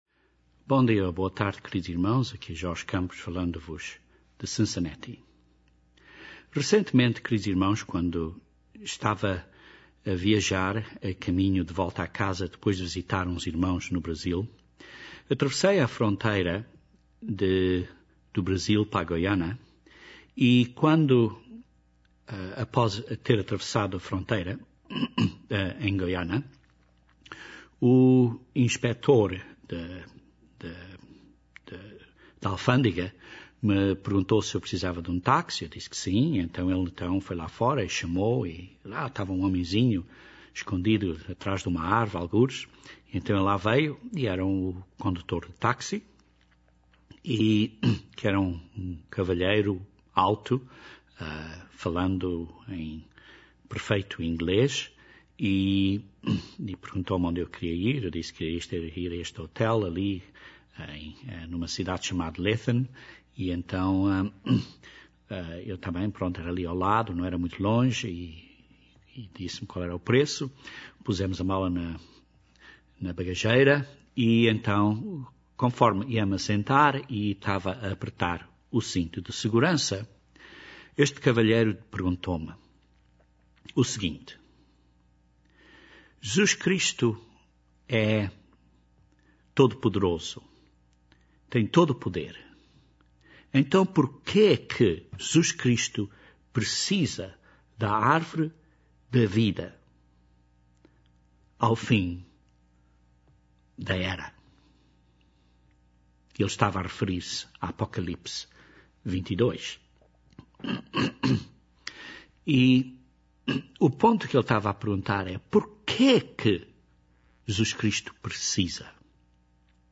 Este sermão analiza este tema das próprias palavras da Bíblia e responde a esta pergunta.